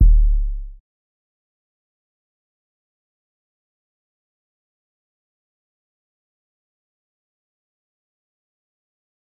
Kicks
JJKicks (2).wav